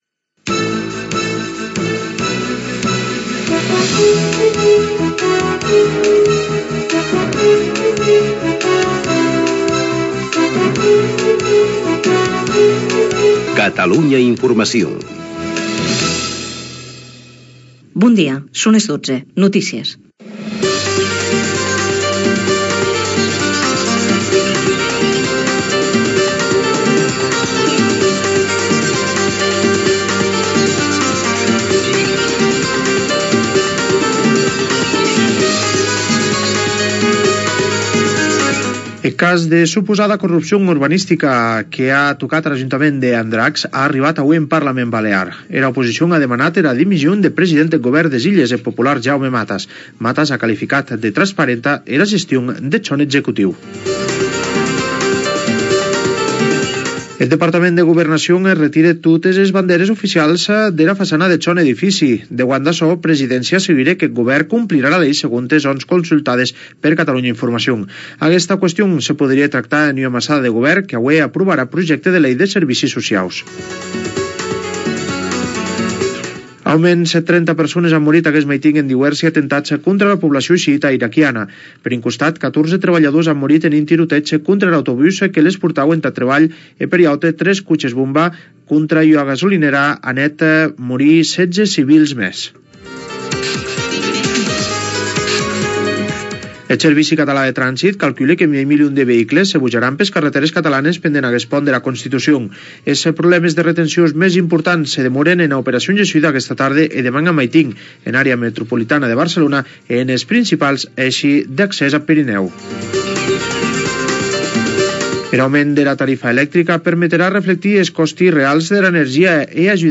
Indicatiu de la ràdio, hora, resum informatiu internacional
celebració de Santa Bàrbara la patrona dels bombers Gènere radiofònic Informatiu